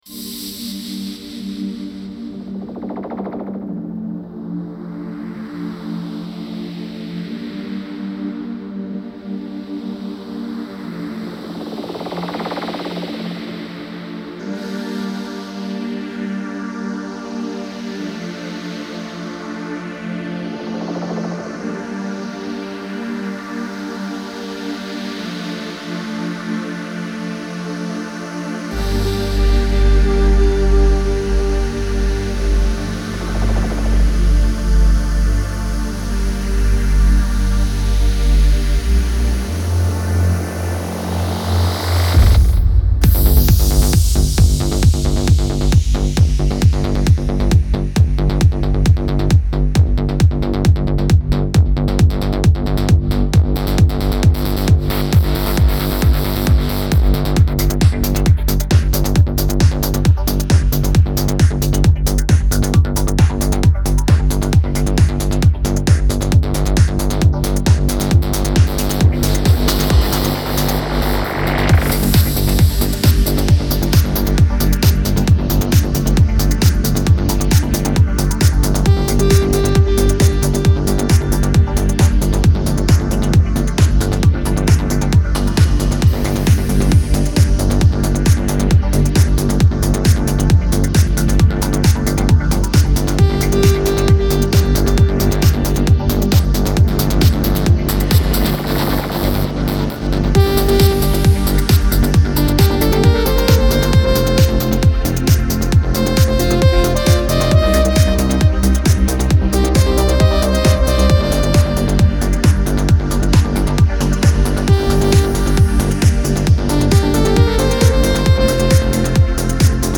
Genre : Électronique, Techno